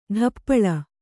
♪ ḍhappaḷa